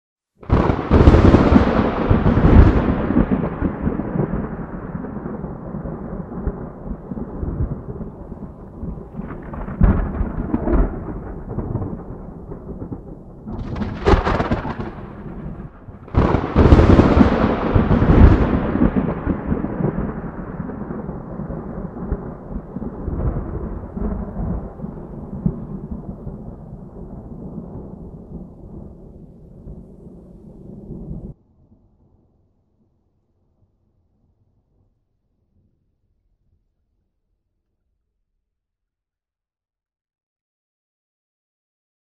airplthunder.mp3